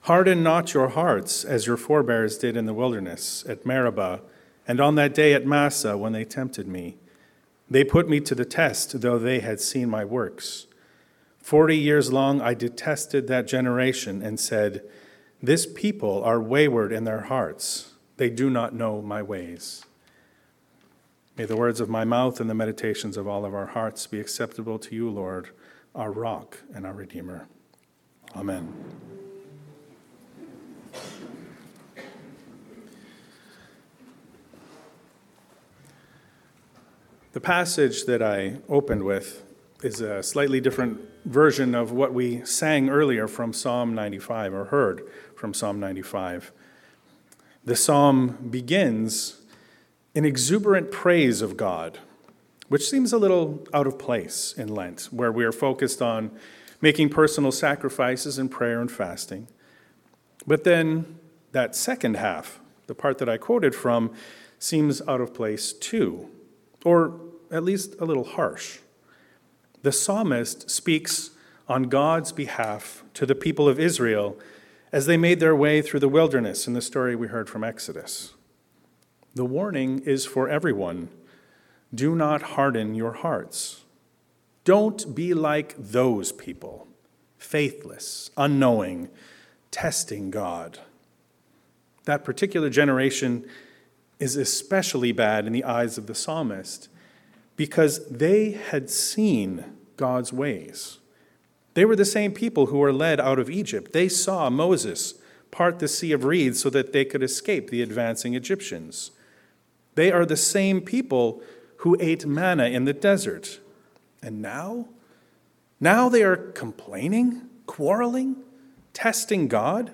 Living Water. A sermon on Exodus 17.1-7, Psalm 95, and John 4.5-42